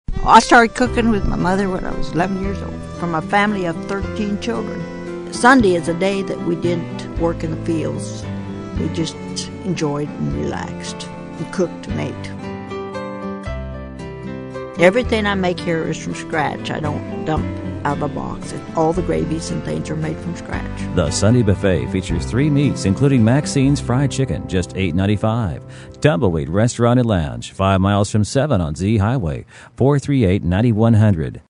Listen to this example of a local “original,” sent to me a few years back by a client who runs a radio station in a small Missouri market: